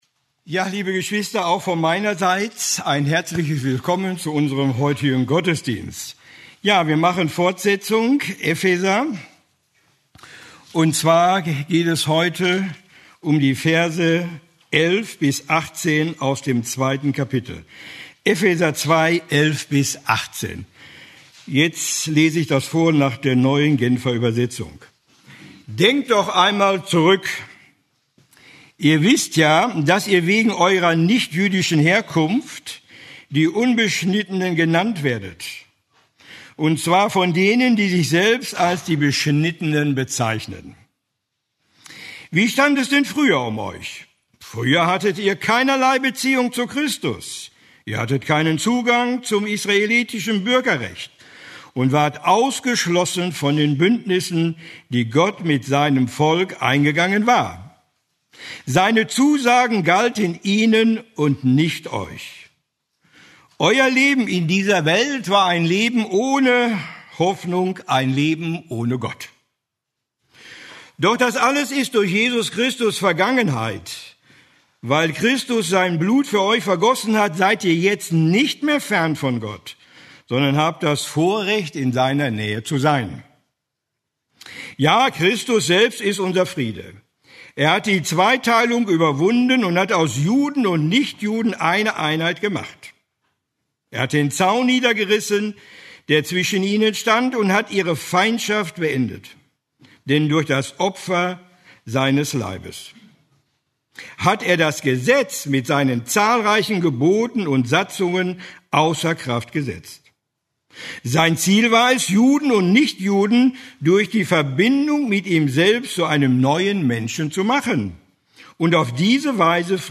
Eine predigt aus der serie "Epheser."